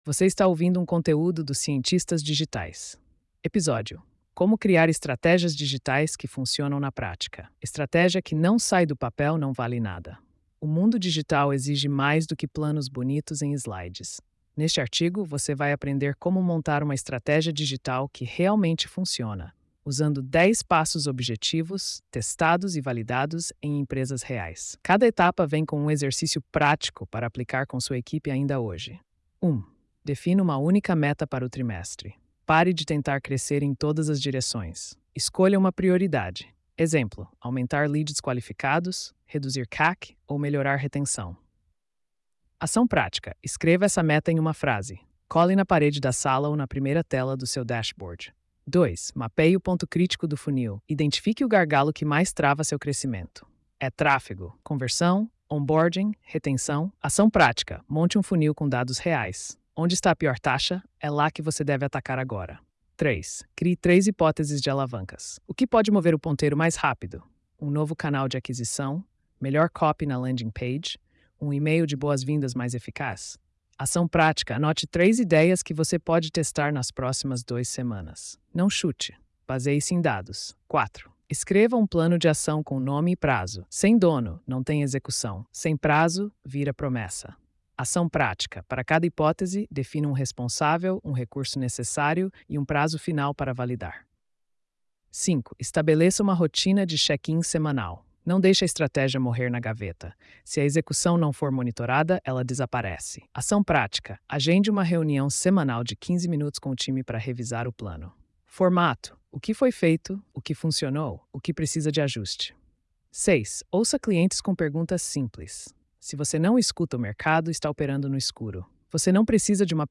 post-3142-tts.mp3